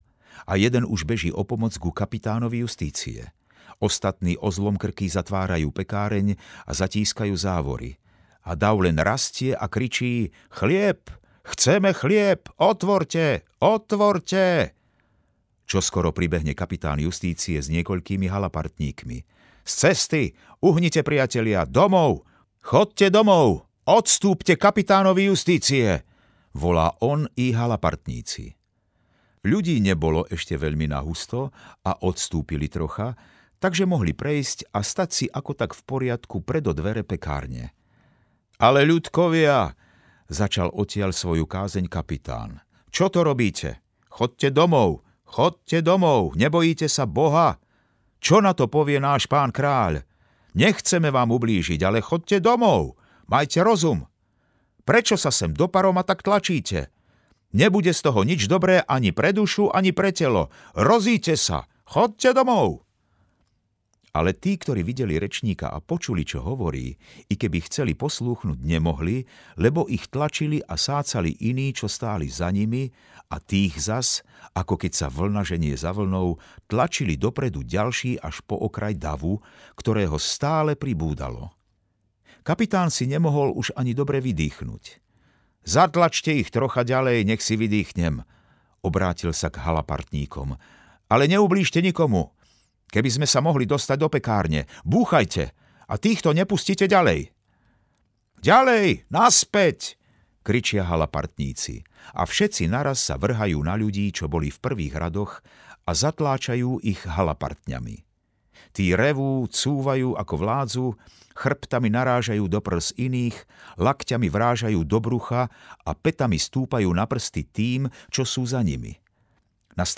Snúbenci/audiokniha
Interpreti Slávka Halčáková a Ján Gallovič